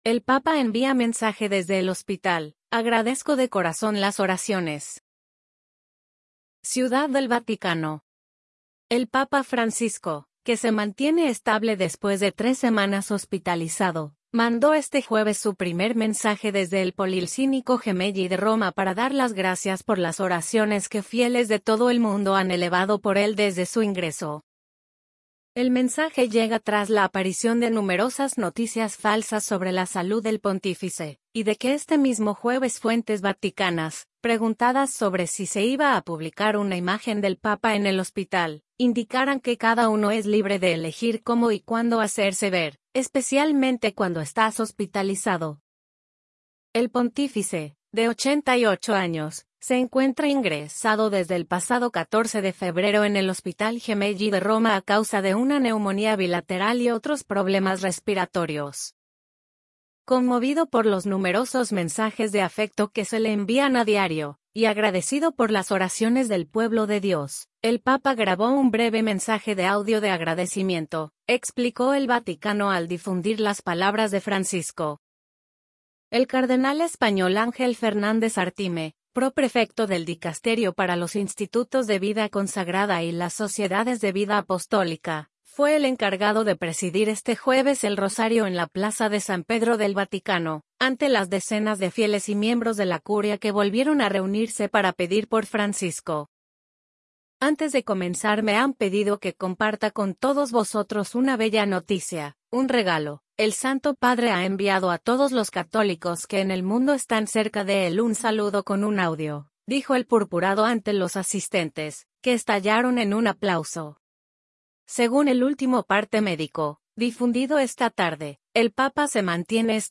“Agradezco de todo corazón las oraciones que hacen por mi salud desde la plaza, los acompaño desde acá. Que Dios los bendiga y que la Virgen los cuide. Gracias”, dice Francisco con una voz muy débil en el mensaje grabado y difundido antes del rezo del Rosario que se celebra.
“Conmovido por los numerosos mensajes de afecto que se le envían a diario y agradecido por las oraciones del pueblo de Dios, el papa grabó un breve mensaje de audio de agradecimiento”, explicó el Vaticano al difundir las palabras de Francisco.